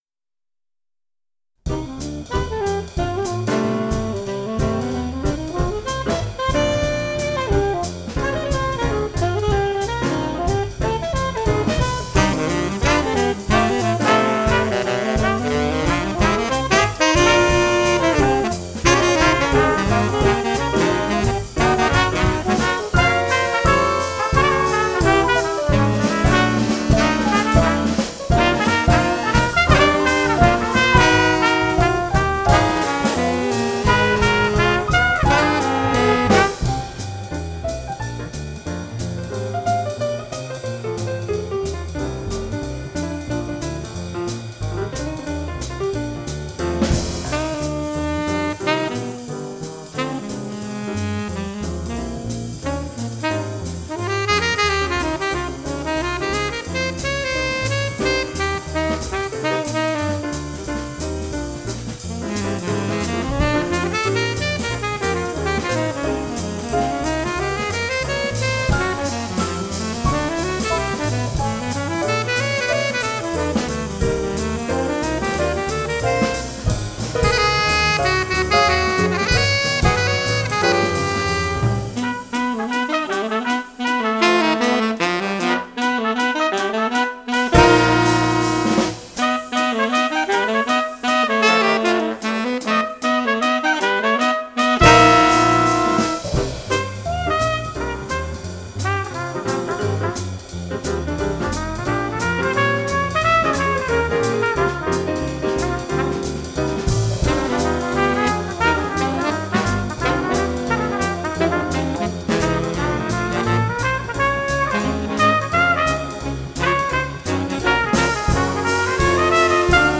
Du swing pour une course urbaine